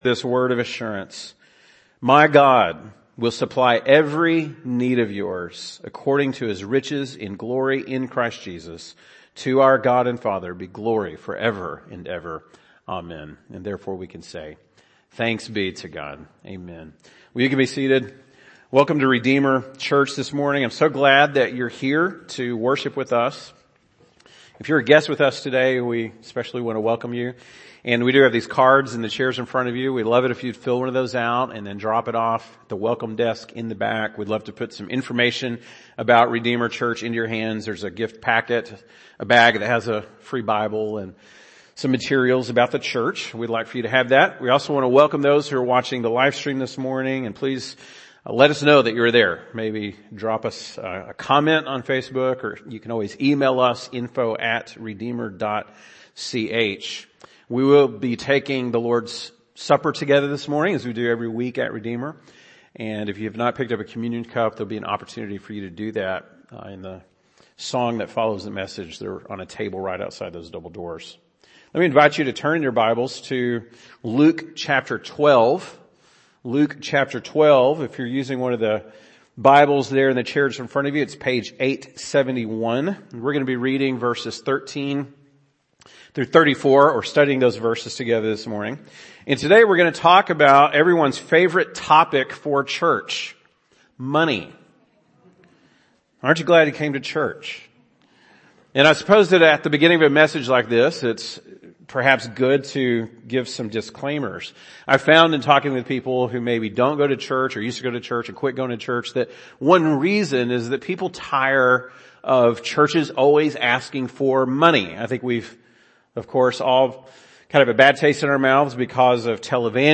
February 13, 2022 (Sunday Morning)